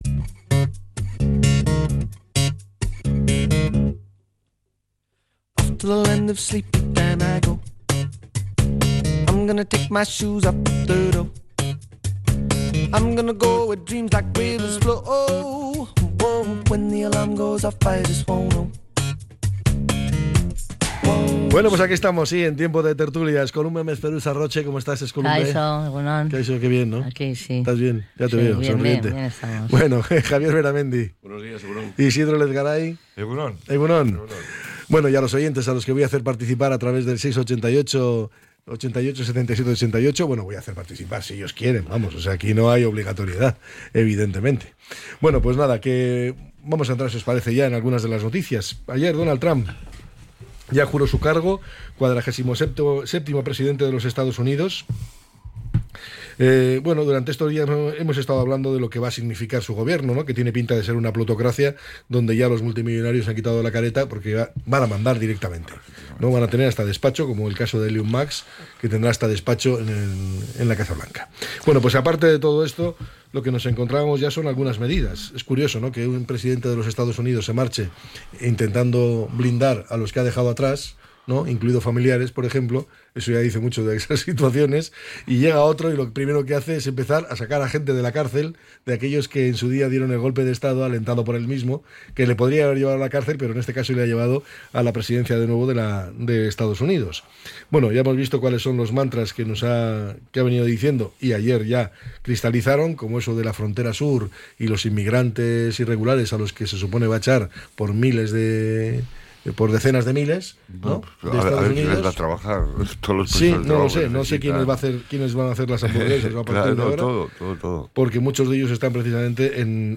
La tertulia 21-01-25.